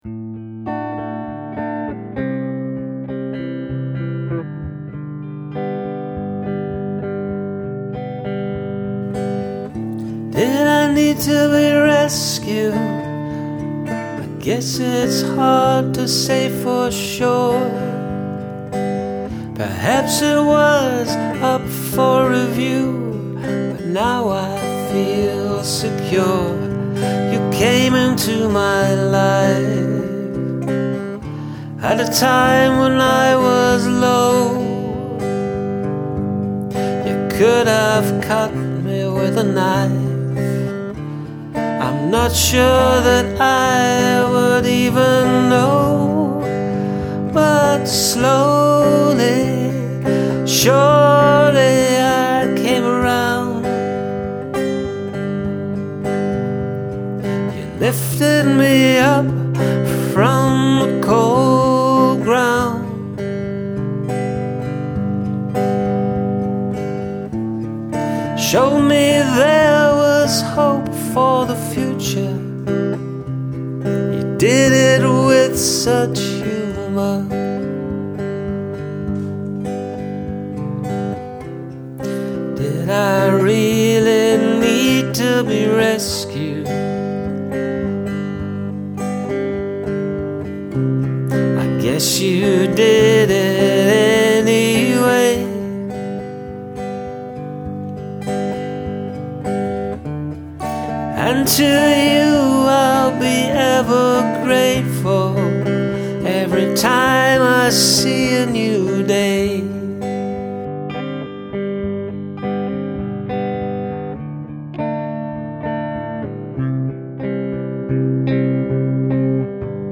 I really like the guitar sound in this.
Nice and gentle song, lovely sentiment and an all arounf good listen.
I can feel the appreciation in the vocal performance.